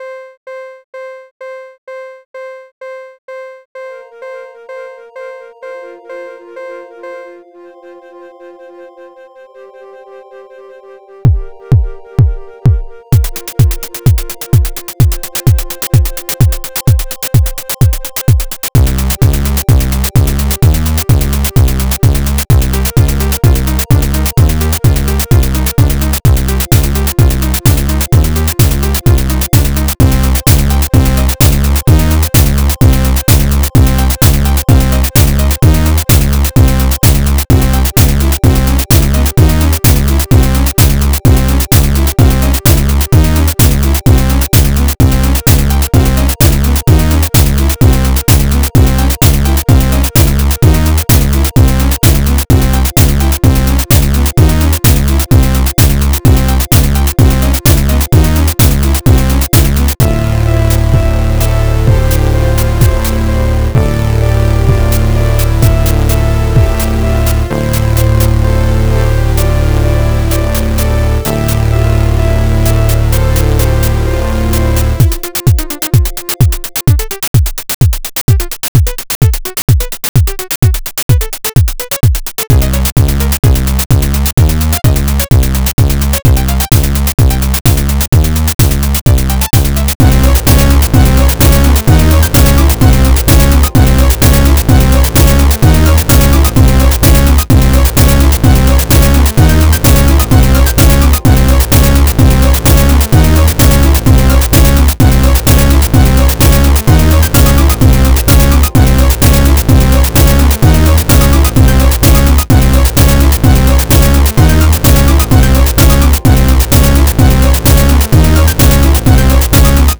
• WAV sample (default: 50%) → Authentic drum machine character
• 12 bass types: Deep, Acid, Wobble, Gritty, PVC, FM, Hollow, Punchy, Resonant, Sub, Pluck
• 4 kick flavors: Standard, Heavy, Tight, Punchy (changes per section)
• Multiple synth voices: Brass, Saw, Bell, Pluck, Square, Sine (for lead/melody/pad)
• 6 effect types: Reverb, Delay, Distortion, Filter, Chorus, Dry